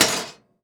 metal_object_small_move_impact_02.wav